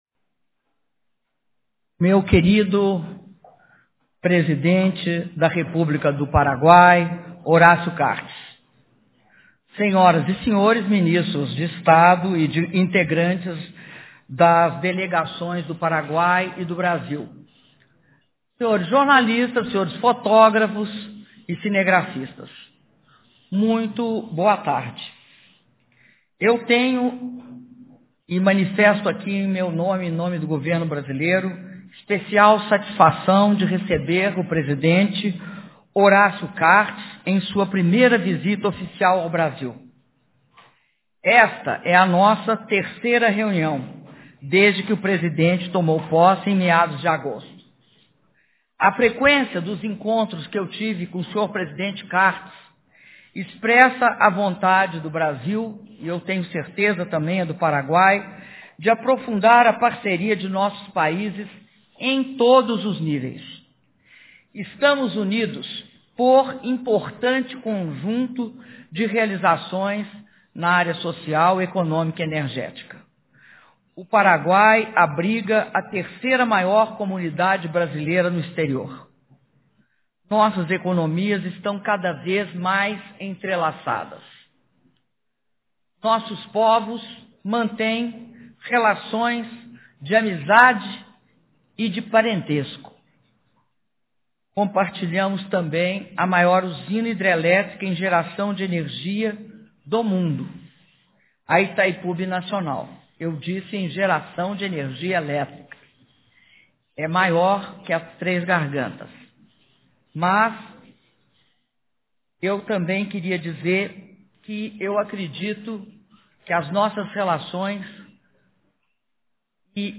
Áudio da declaração à imprensa da Presidenta da República, Dilma Rousseff, após encontro bilateral com o presidente da República do Paraguai, Horacio Cartes - Brasília/DF (10min28s)